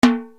SP TIMB1.wav